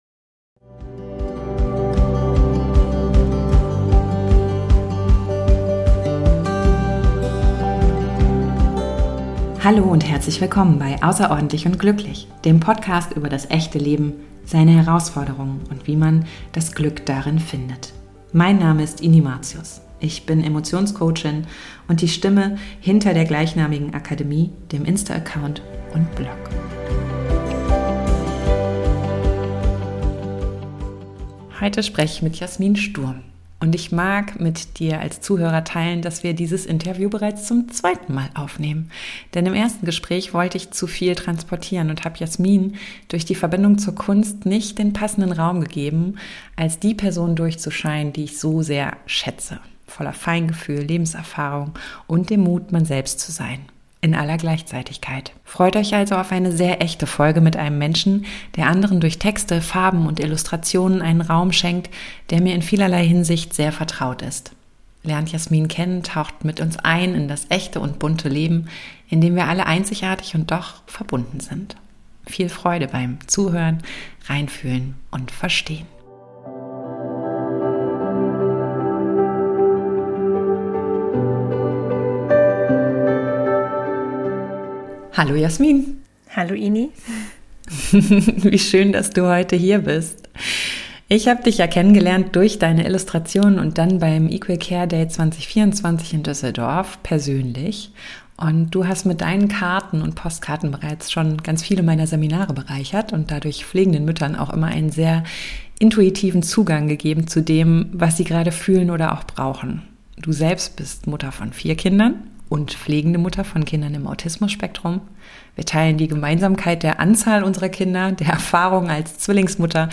Und ich mag mit dir teilen, dass wir dieses Interview bereits zum zweiten Mal aufnehmen.